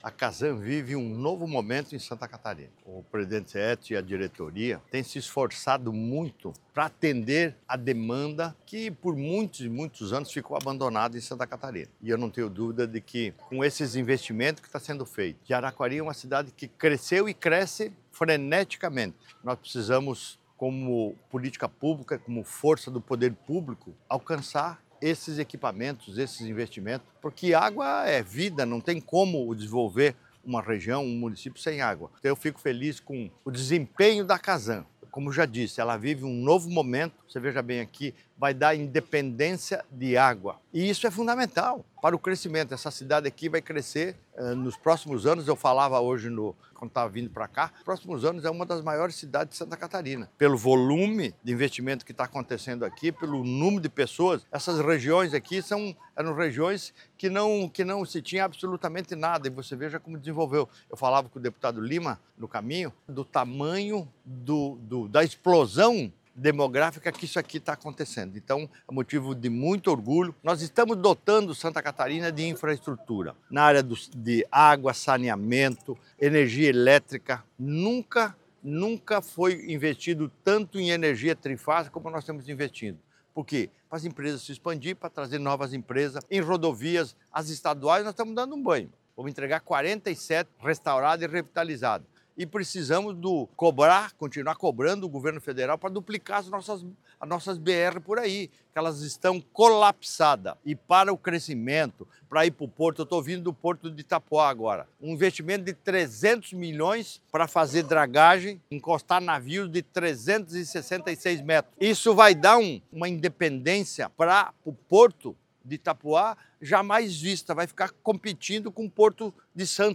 O governador Jorginho Mello ressaltou a importância dessa independência para o município e destacou também e relevância de Araquari no cenário estadual: